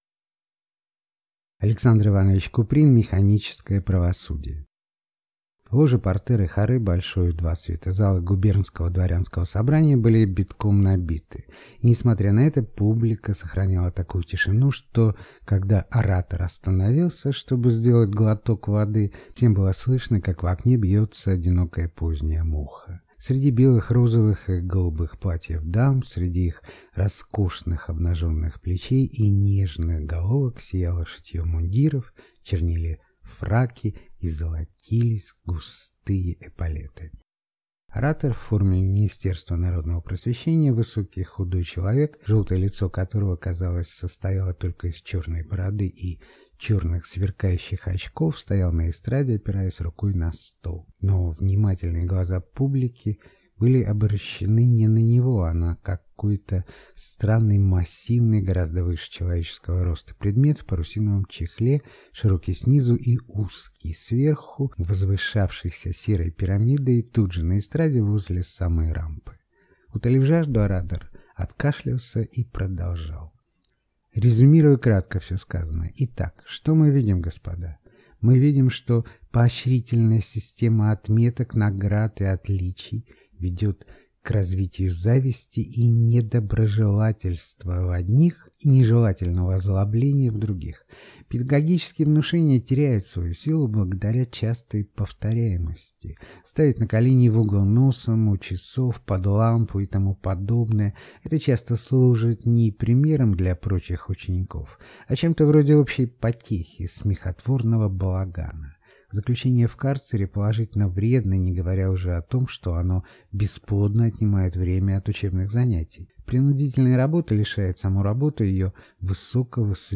Аудиокнига Механическое правосудие | Библиотека аудиокниг